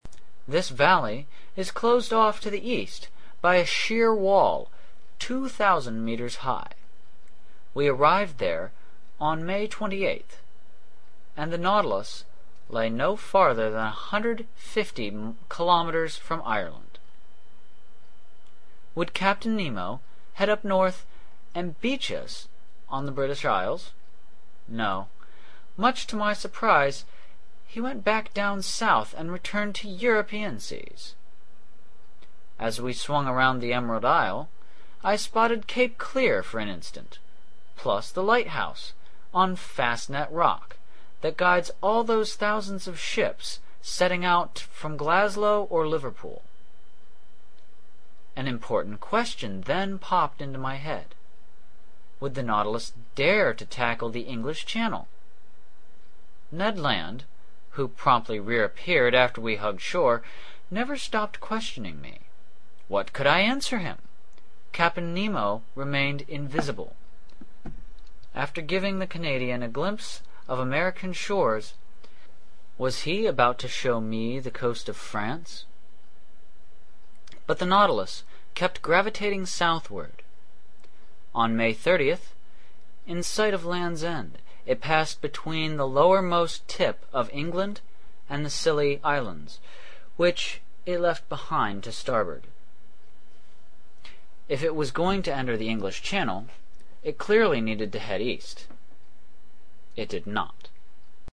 英语听书《海底两万里》第535期 第33章 北纬47.24度, 西经17.28度(10) 听力文件下载—在线英语听力室
在线英语听力室英语听书《海底两万里》第535期 第33章 北纬47.24度, 西经17.28度(10)的听力文件下载,《海底两万里》中英双语有声读物附MP3下载